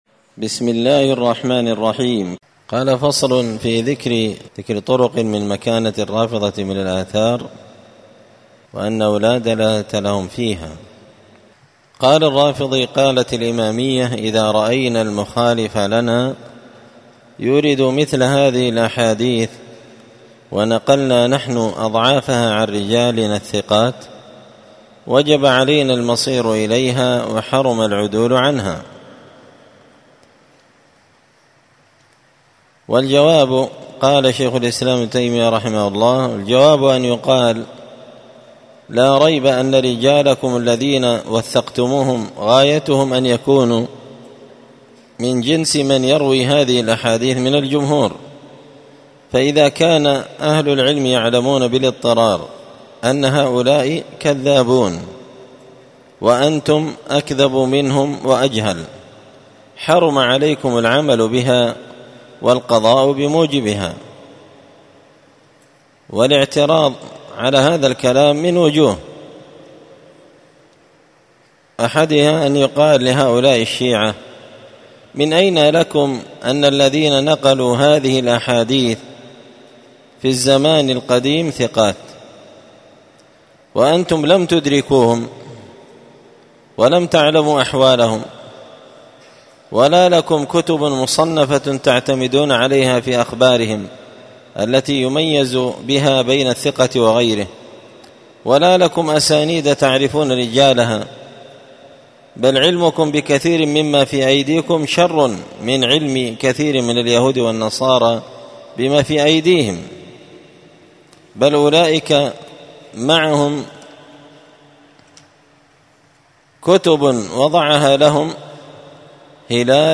الدرس الثالث بعد المائتين (203) فصل في ذكر طرق من مكانة الرافضة من الآثار وأنه لادلالة لهم فيها